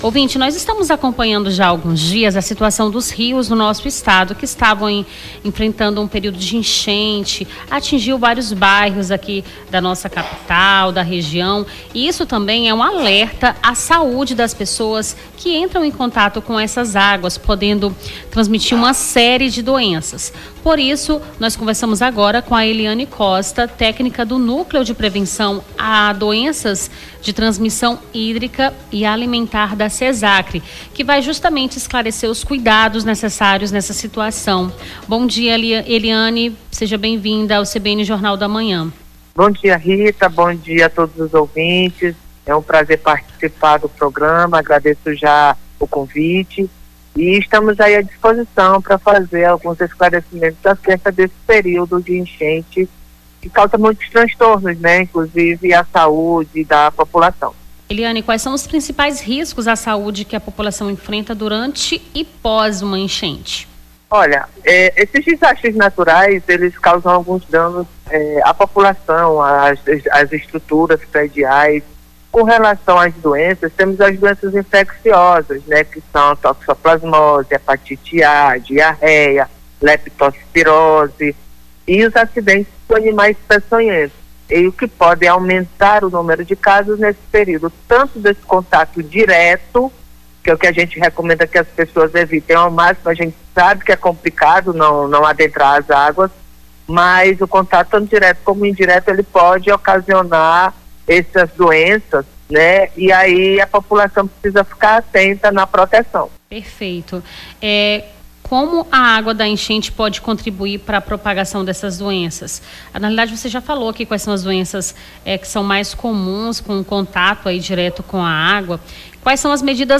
Nome do Artista - CENSURA - ENTREVISTA PREVENÇÃO DOENÇAS HÍDRICAS (26-03-25).mp3